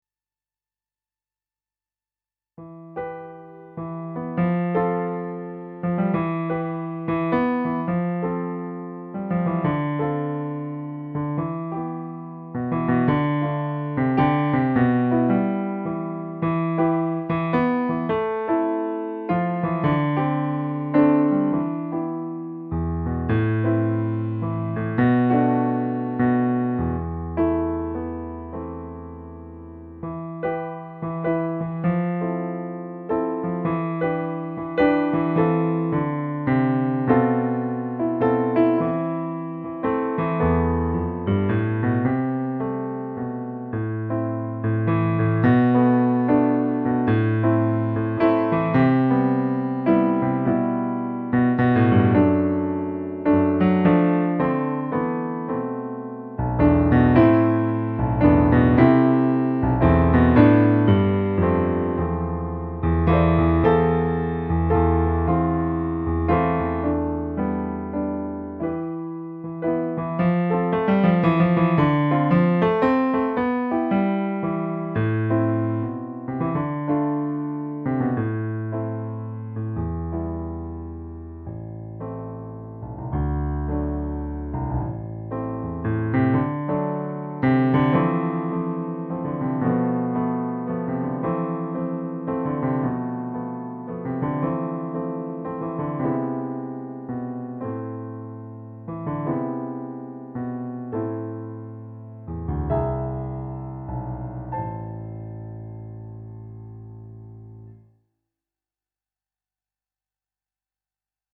Ici, la musique me parait un peu nostalgique par rapport au titre qui pourrait plutôt faire supposer une impatience fébrile et enthousiaste.